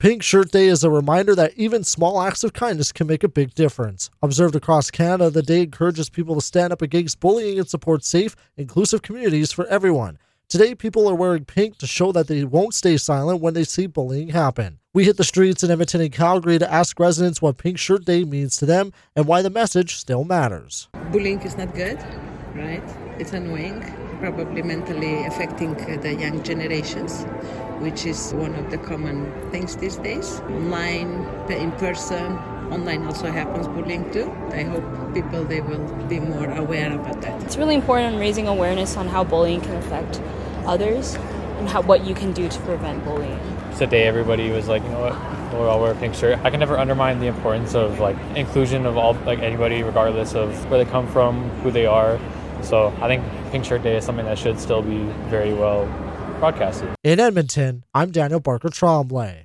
Community members in Edmonton and Calgary where asked what Pink Shirt Day means to them – and why the message still matters today.